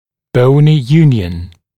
[‘bəunɪ ‘juːnjən][‘боуни ‘йу:нйэн]костное сращение